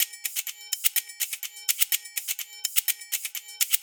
Live Percussion A 11.wav